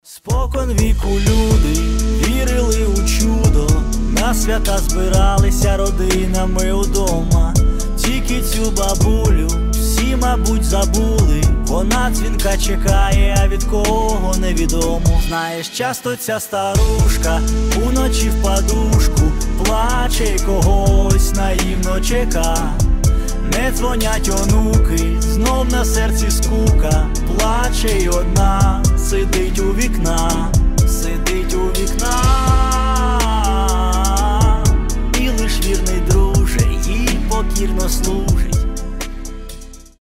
душевные
грустные
Cover
печальные
пародии